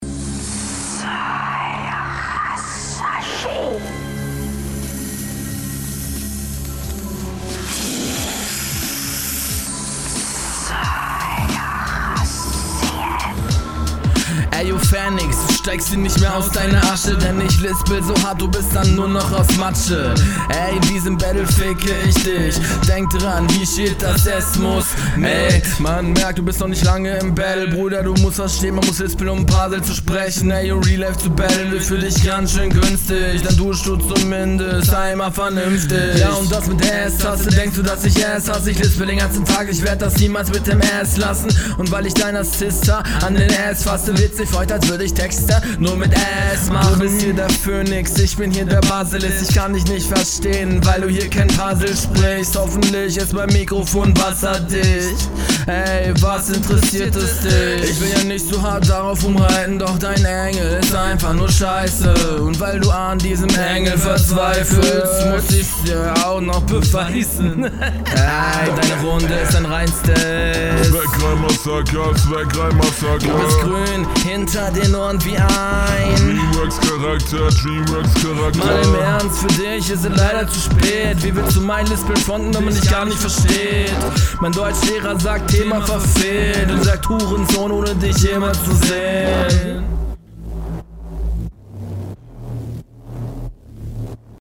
Du klingst zumindest so als hättest du Bock auf die Runde!